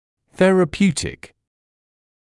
[ˌθerə’pjuːtɪk][ˌсэрэ’пйуːтик]терапевтический